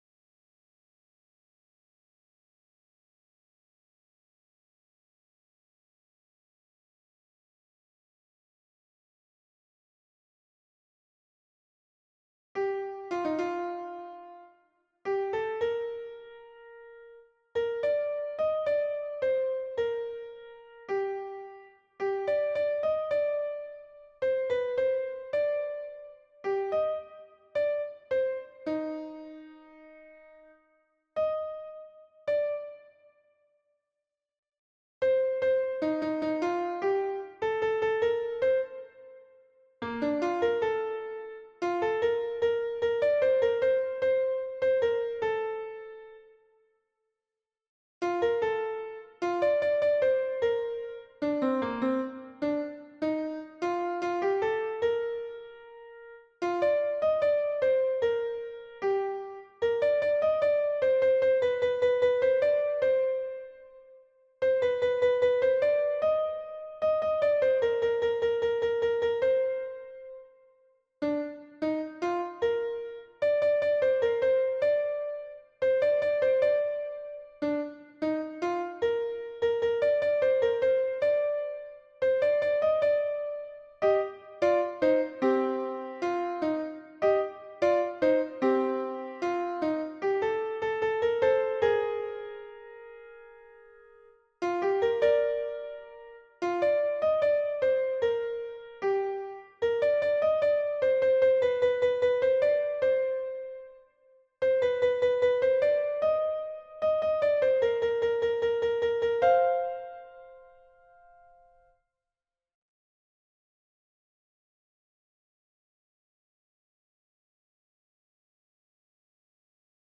MP3 version piano
Soprano